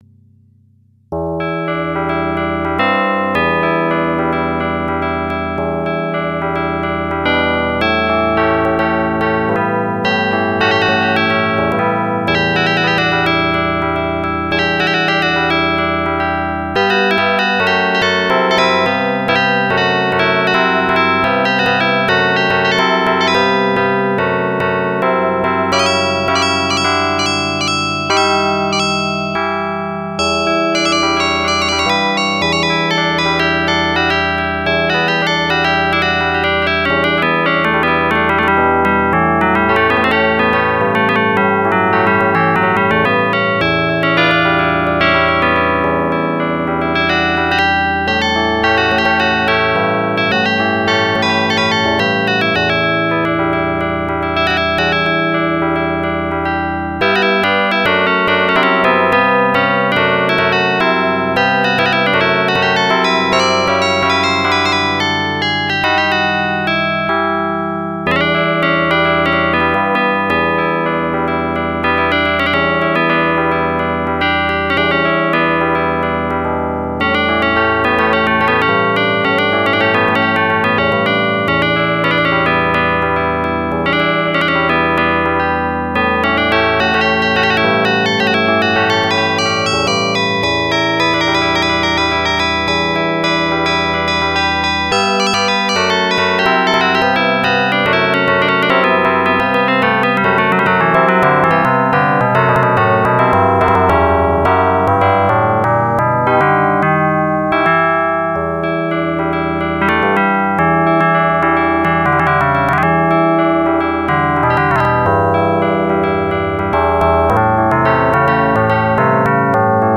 file ogg facite per guittabl era monophonic, que sona bastante ben.